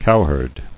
[cow·herd]